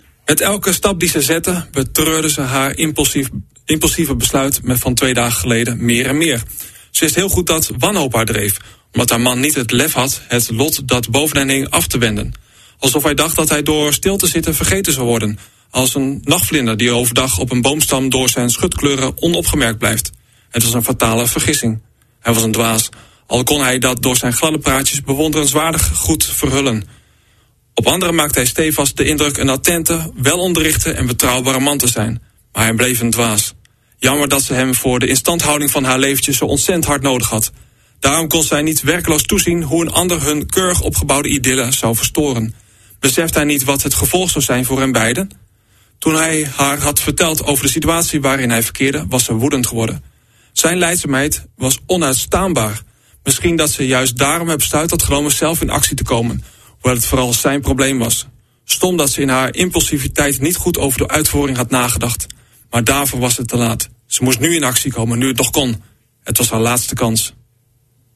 Tijdens de uitzending las ik een stukje voor uit De laatste kans.